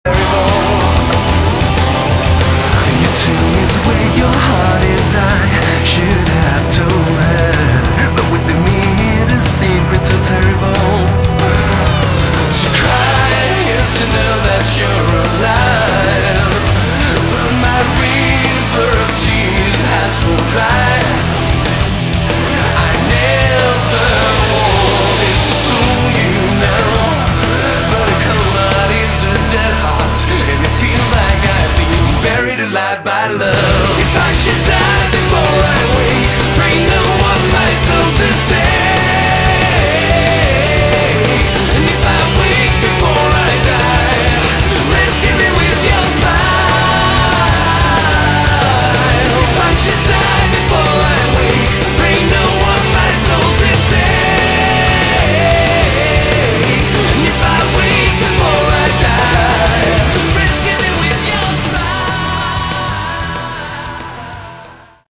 Category: Rock